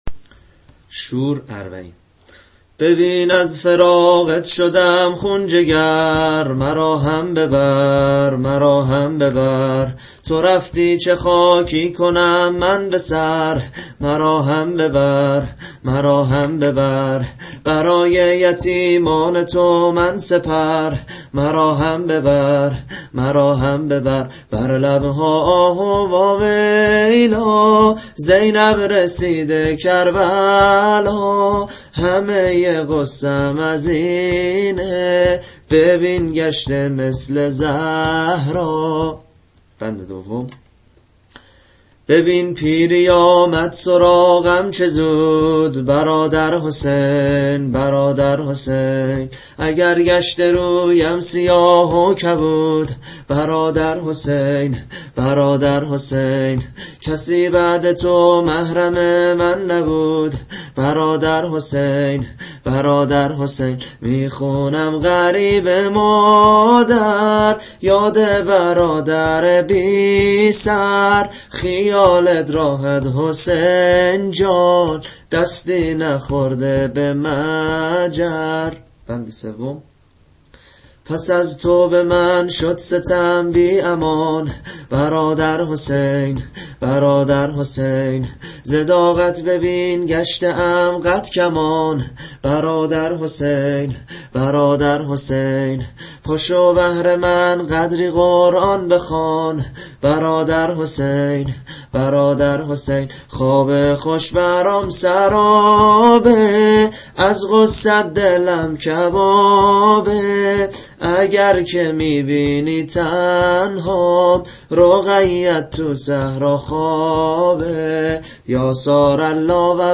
نوحه به همراه سبک